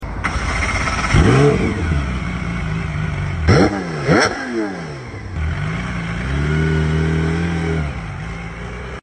Cherry Red Metallic Singer Porsche sound effects free download